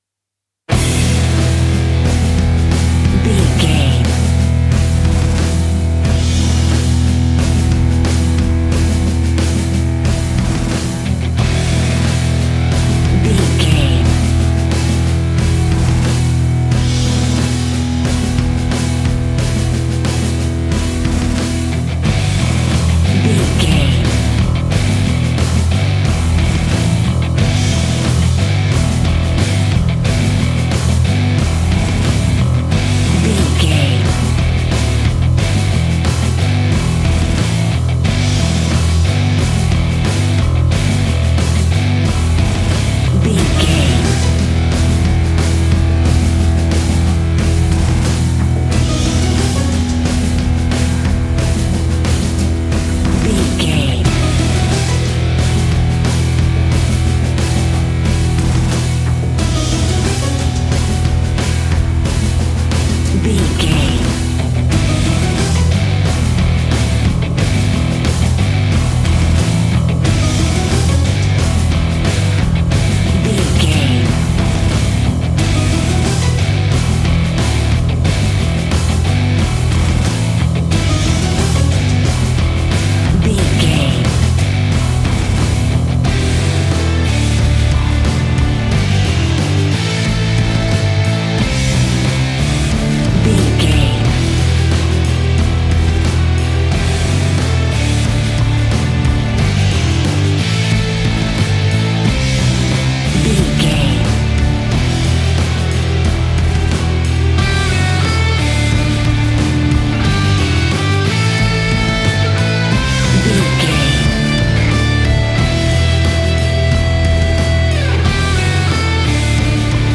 Epic / Action
Fast paced
Aeolian/Minor
hard rock
scary rock
instrumentals
Heavy Metal Guitars
Metal Drums
Heavy Bass Guitars